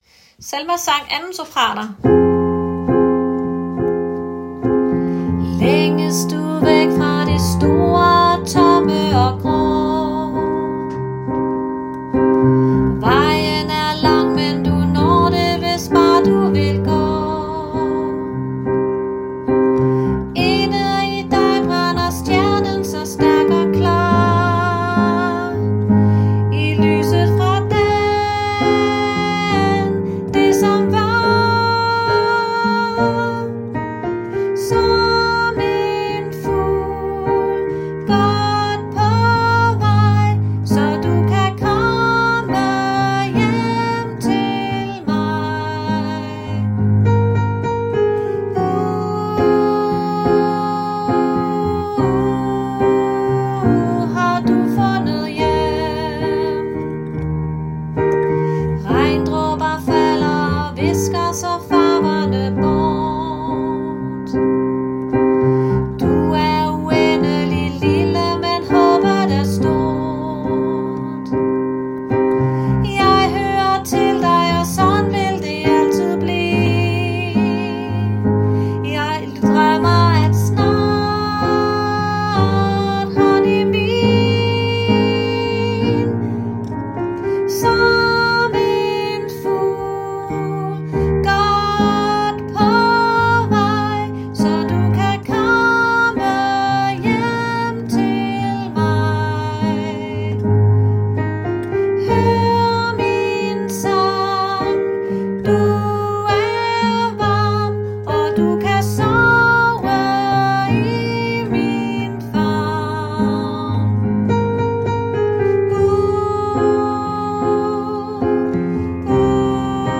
Selmas sang – 2. sopran
Selmas_sang_2.-Sopran.m4a